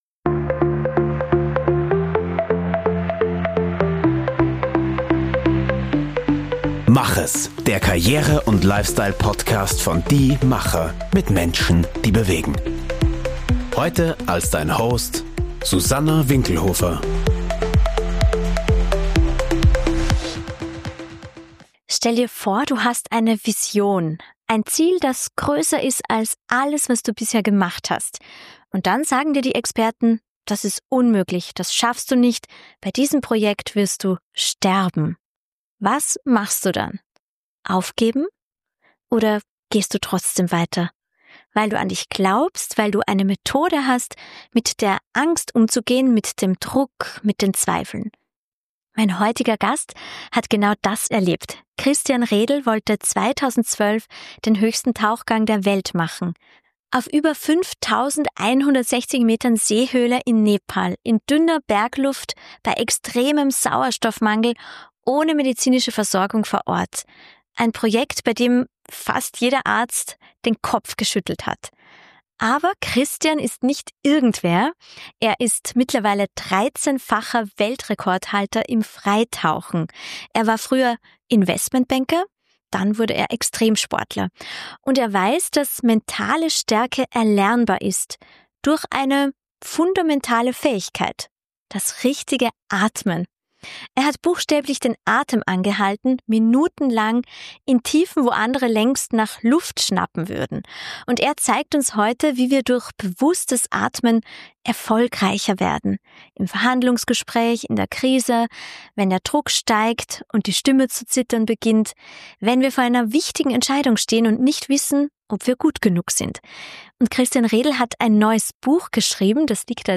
Ein Gespräch über Limits, die nur im Kopf existieren.